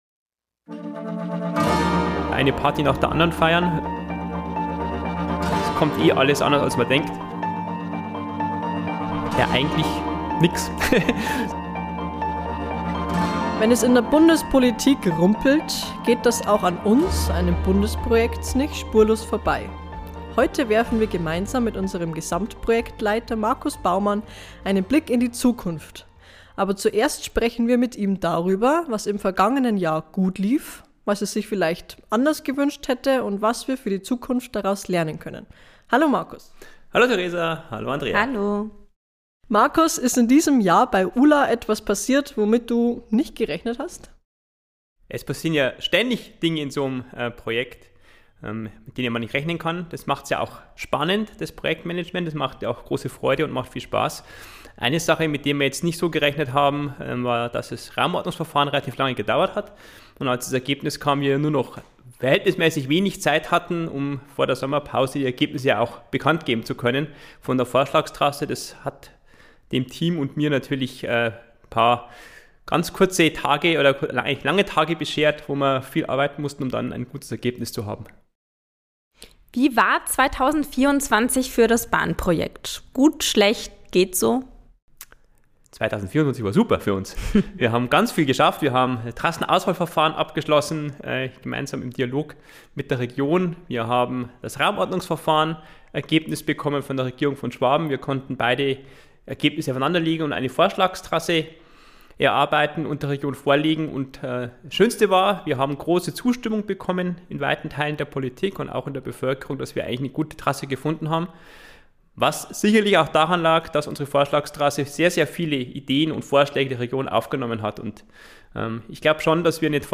Beschreibung vor 1 Jahr Wir haben lange damit gerechnet, dass sich der Deutsche Bundestag Anfang 2025 mit unserem Projekt befasst. Dieses Datum wird nicht zu halten sein, wenn der Bundestag vorzeitig aufgelöst wird. Ein Gespräch